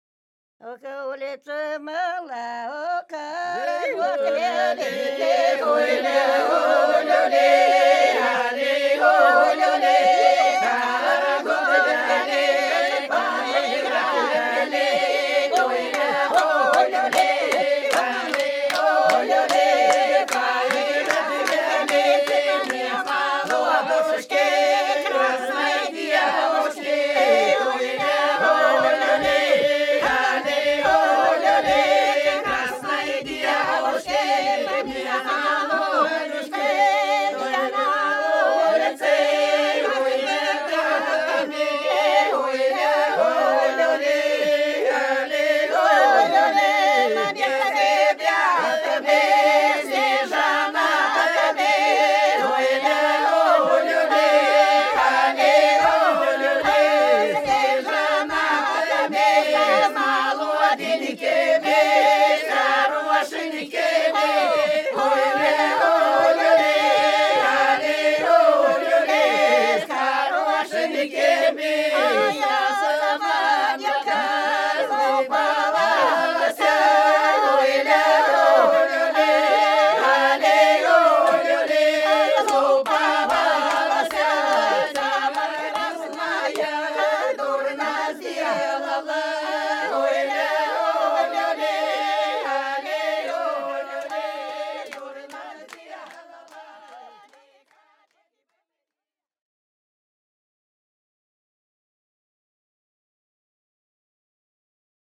Ансамбль села Хмелевого Белгородской области Улица мала, карагод велик (карагодная)